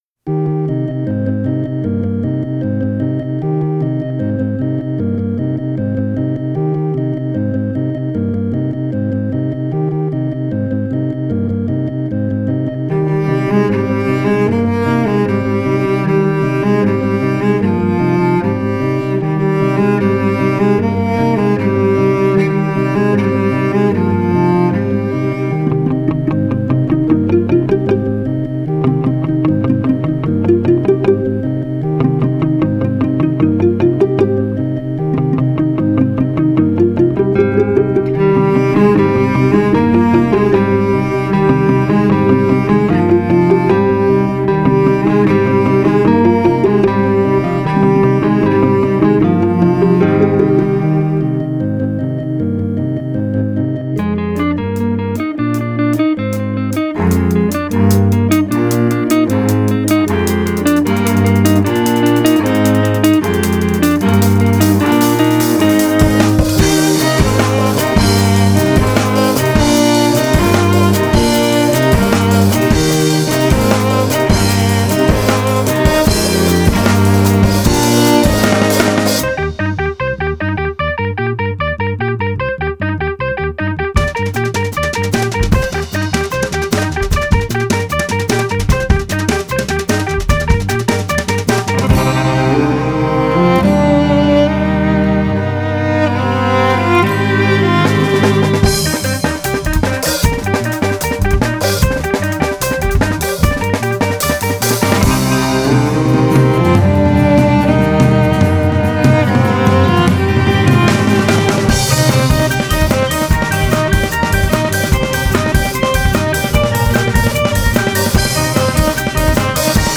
mélangent avec brio pop et musique classique